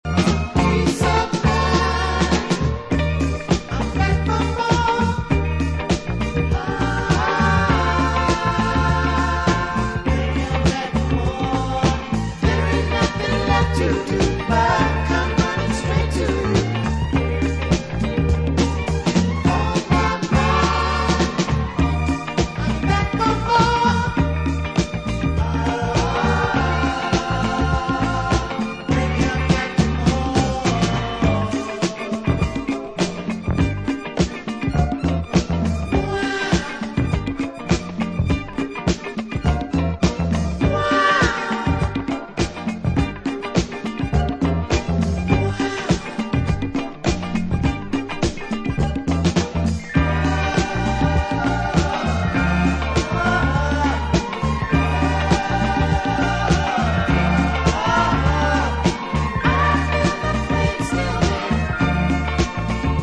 哀愁を帯びつつもソウルフルに盛り上がる傑作！